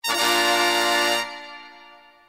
tada